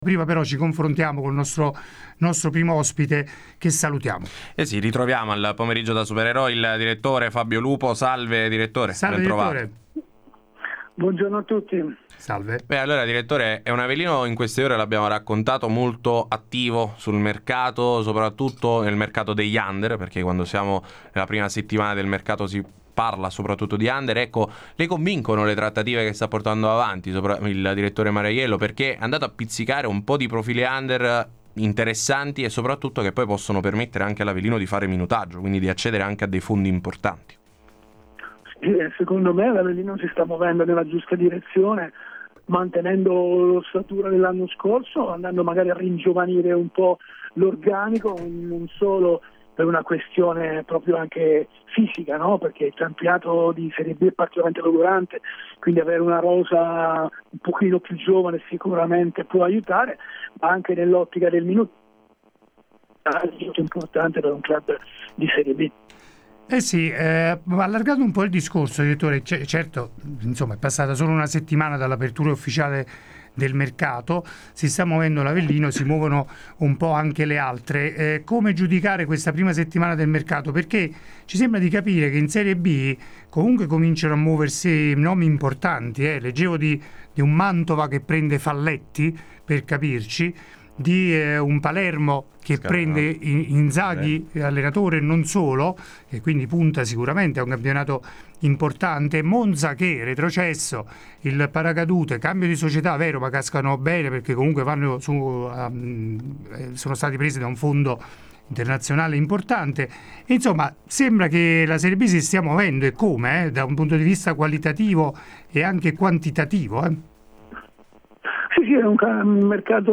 Ospite de Il Pomeriggio da Supereroi di Radio Punto Nuovo